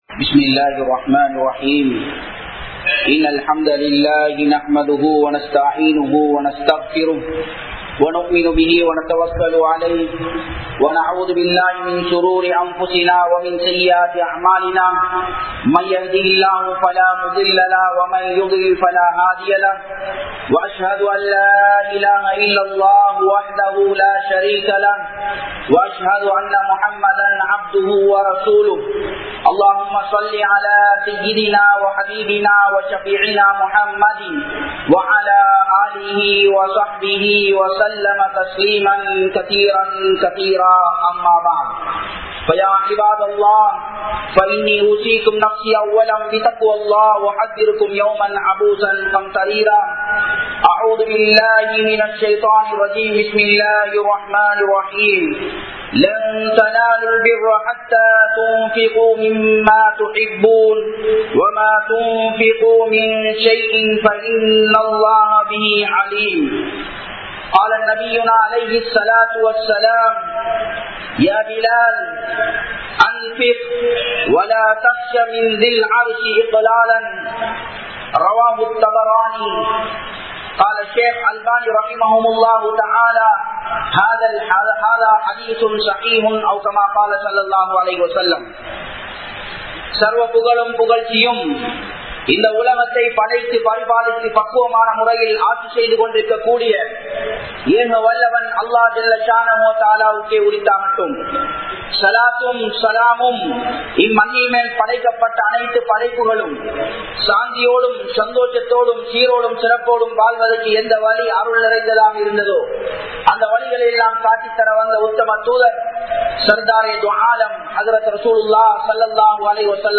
Ungal Selvathai Irai Valiyil Selavu Seyyunkal(உங்கள் செல்வத்தை இறை வழியில் செலவு செய்யுங்கள்) | Audio Bayans | All Ceylon Muslim Youth Community | Addalaichenai
Muhiyadeen Jumua Masjith